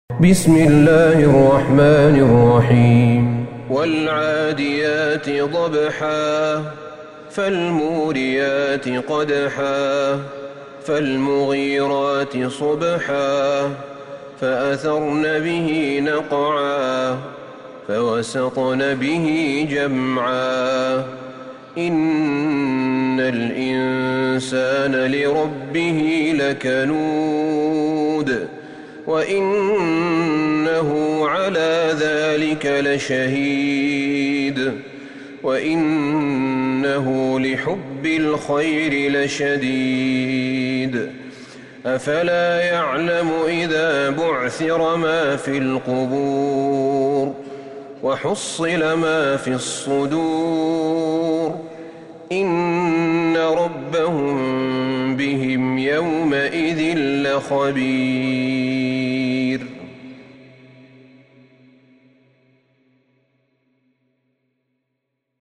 سورة العاديات Surat Al-Adiyat > مصحف الشيخ أحمد بن طالب بن حميد من الحرم النبوي > المصحف - تلاوات الحرمين